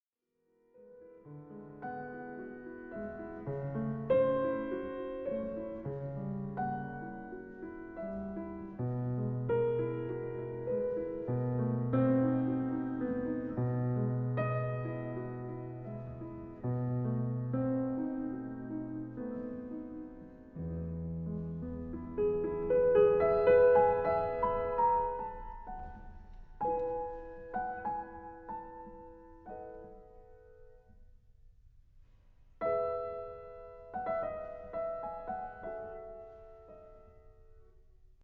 Towards the end he returns to the upper, soprano-like register:
It feels like angels talking to us.